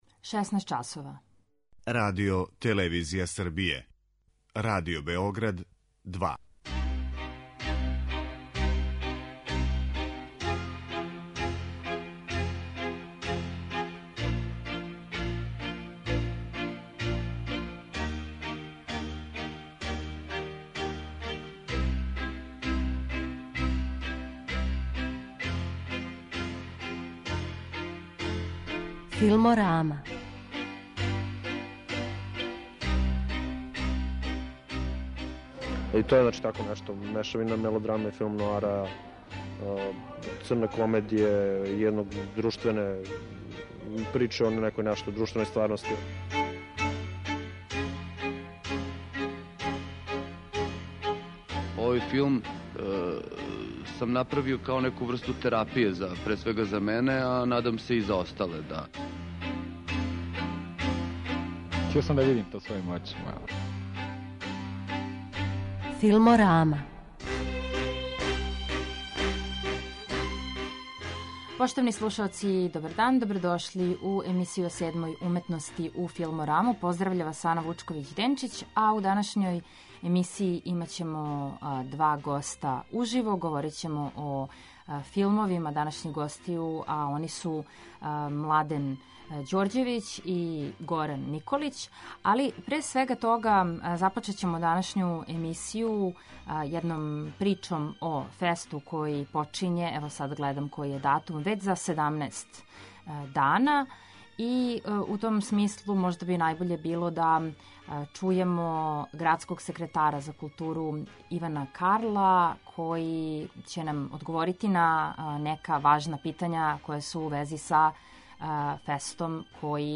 Чућемо градског секретара за културу Ивана Карла, који ће говорити о селекцијама, филмовима, гостима, правцу у коме се ФЕСТ креће.